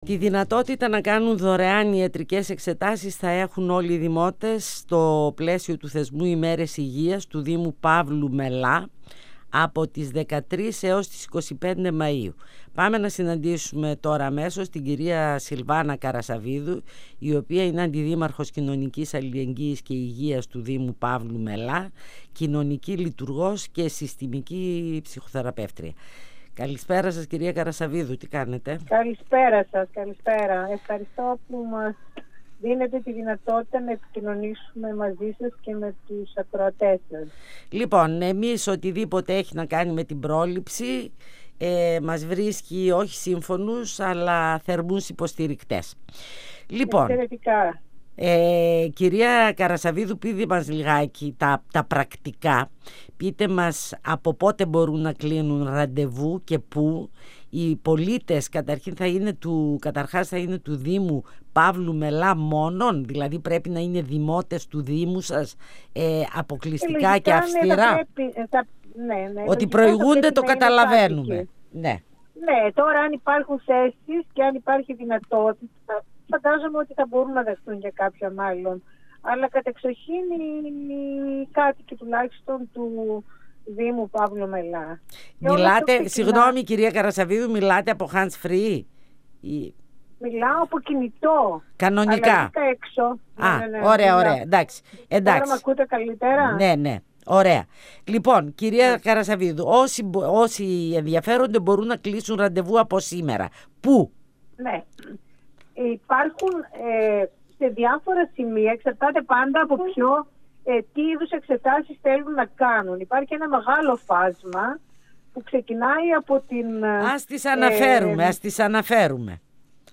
H αντιδήμαρχος κοινωνικής αλληλεγγύης και υγείας του δήμου Παύλου Μελά, Συλβάνα Καρασαββίδου, στον 102FM | «Φωνές πίσω από τη μάσκα» | 22.04.2024